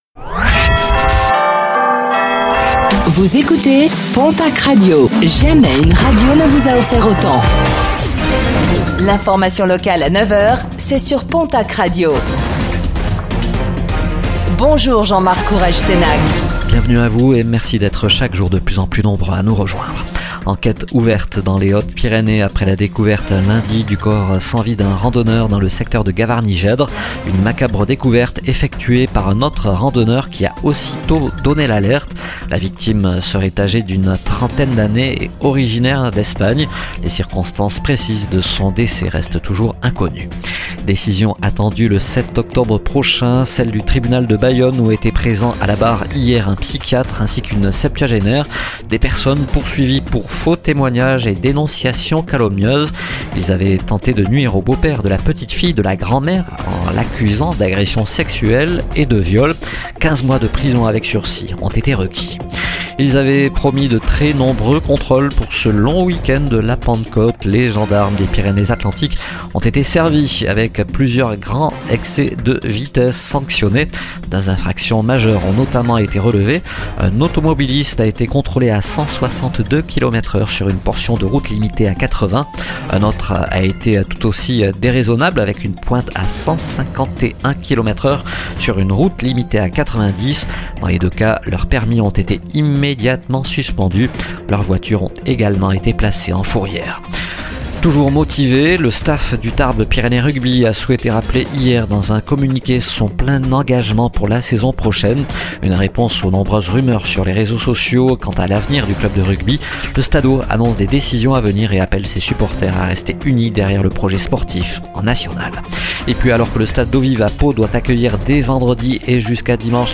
Réécoutez le flash d'information locale de ce mercredi 11 juin 2025, présenté par